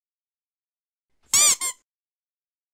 جلوه های صوتی
دانلود صدای اردک 1 از ساعد نیوز با لینک مستقیم و کیفیت بالا
برچسب: دانلود آهنگ های افکت صوتی اشیاء دانلود آلبوم صدای سوت اردک اسباب بازی از افکت صوتی اشیاء